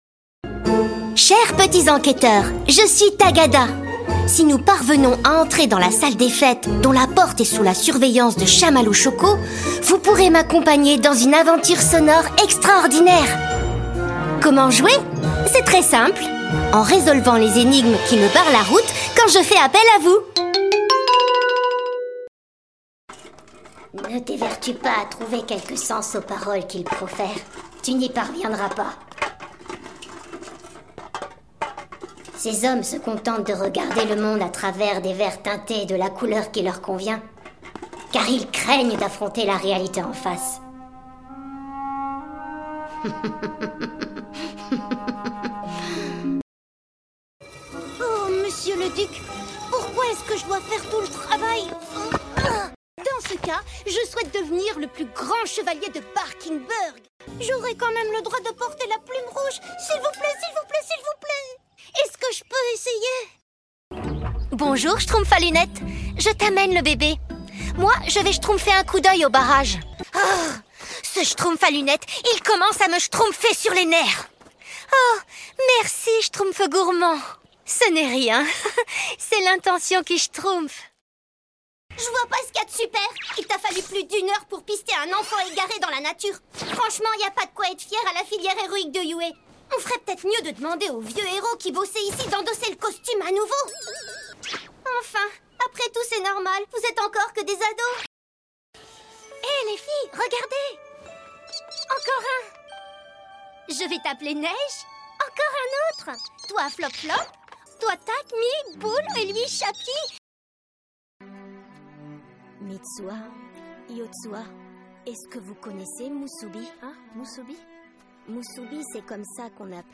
Démo dessins animés jeux vidéo
J'ai une voix jeune, légère, cristalline et pétillante...